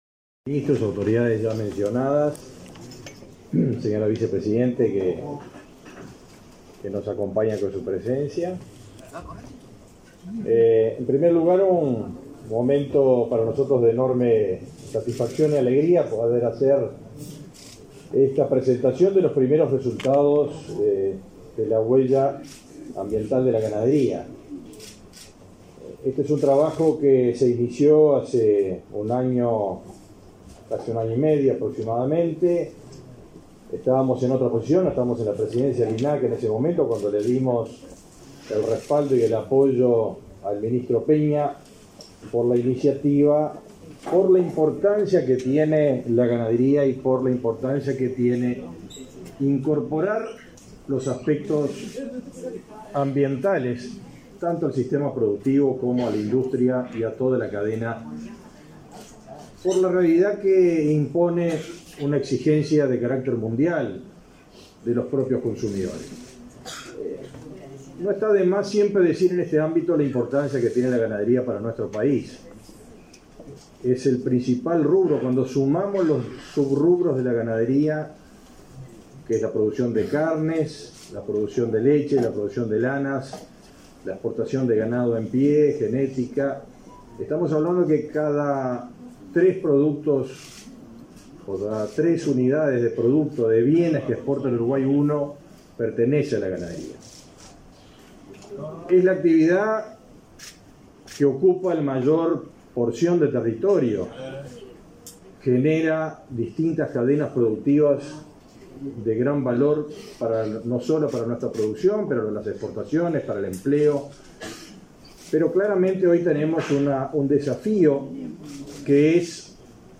Conferencia de prensa por la presentación de la Huella Ambiental de la Ganadería del Uruguay